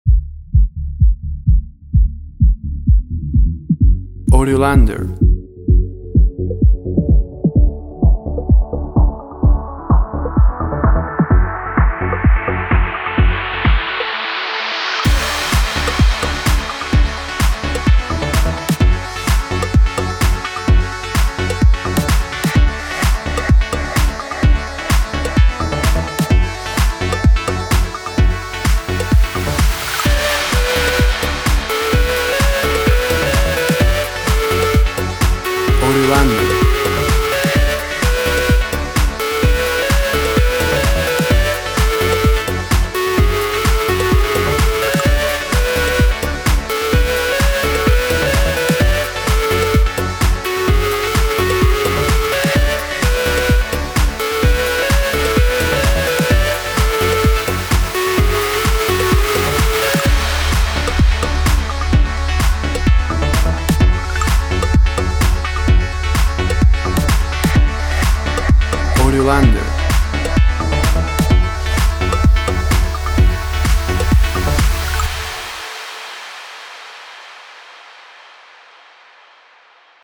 Electronic dance track.
Tempo (BPM) 128